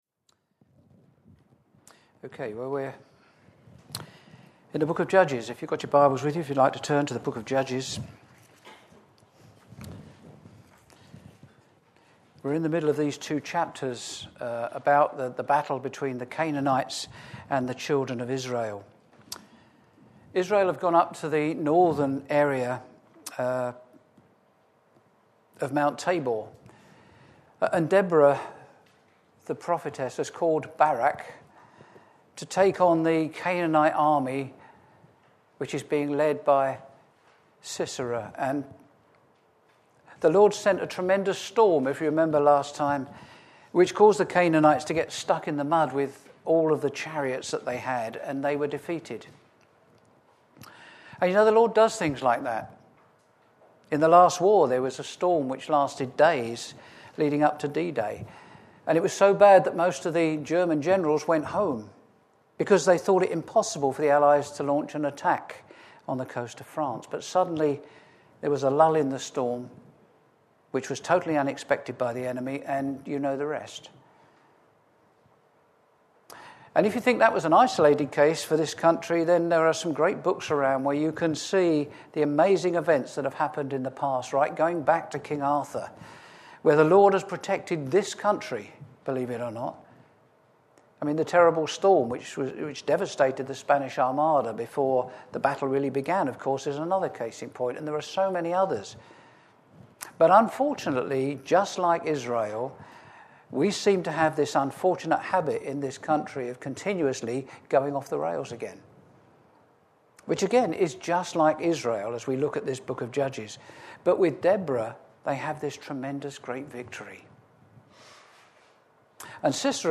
Share this: Click to share on Twitter (Opens in new window) Click to share on Facebook (Opens in new window) Click to share on WhatsApp (Opens in new window) Series: Sunday morning studies Tagged with Verse by verse